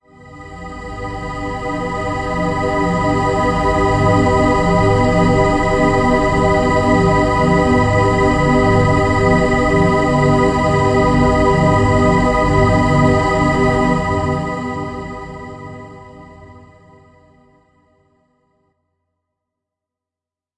描述：不和谐的循环
Tag: 75 bpm Ambient Loops Synth Loops 4.31 MB wav Key : E